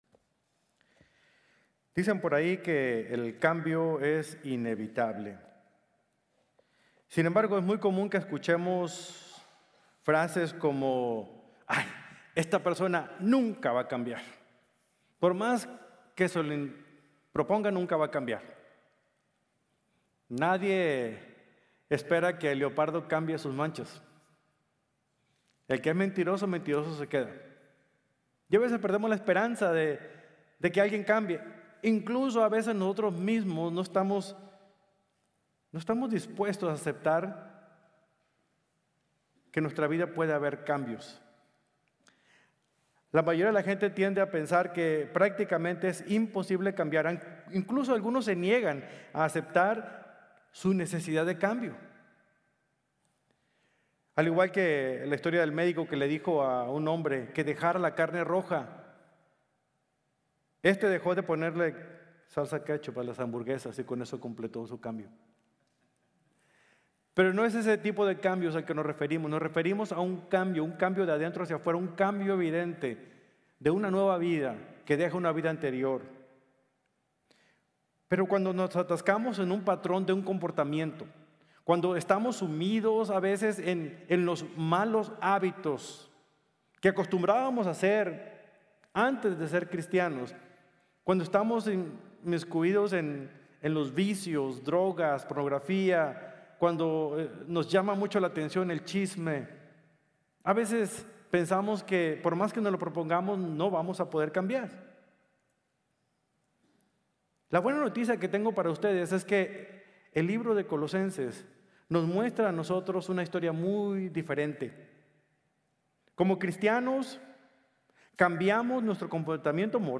Cristo en Ti | Sermon | Grace Bible Church